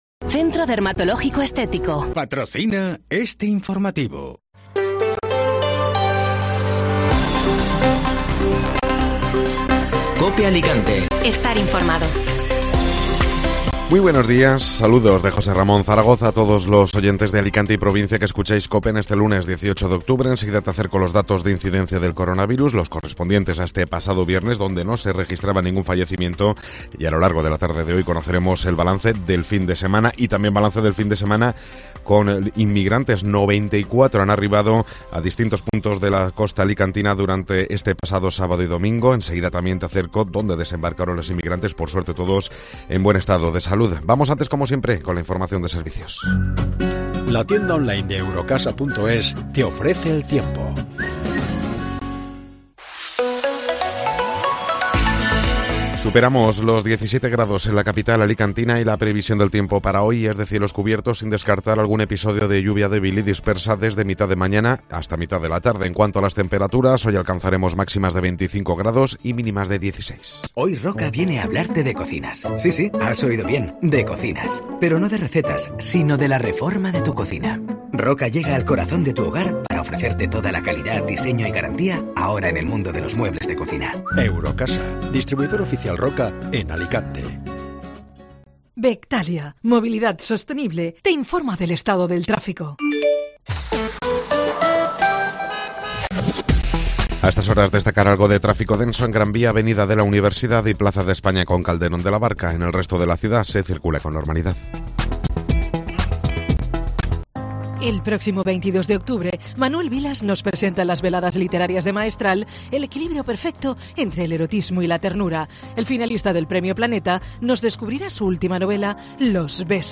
Informativo Matinal (Lunes 18 de Octubre)